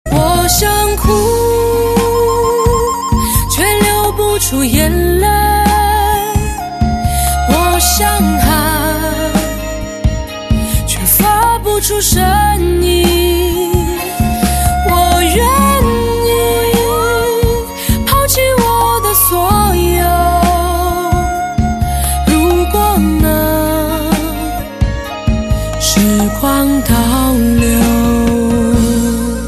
M4R铃声, MP3铃声, 华语歌曲 30 首发日期：2018-05-15 02:48 星期二